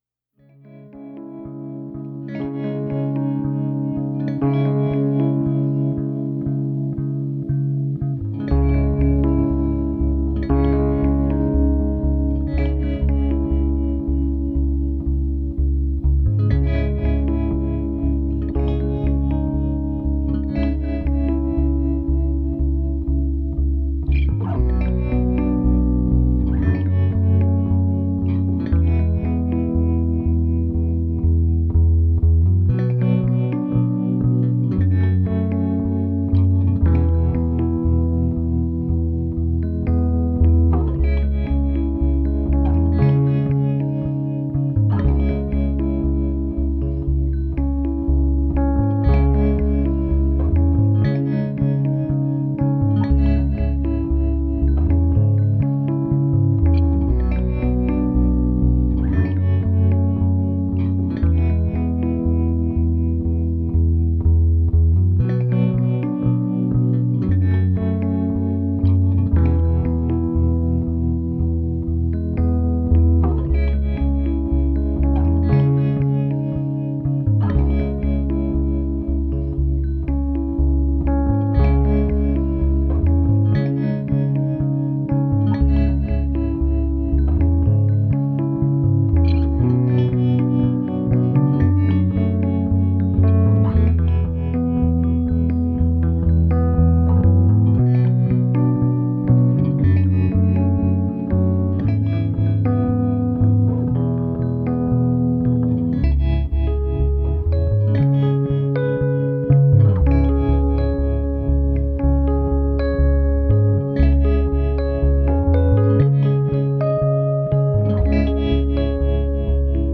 Genre: Indie, Alternative Rock, Original Soundtrack